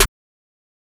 MZ Snare [Metro Bounce Lo].wav